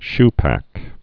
(shpăk)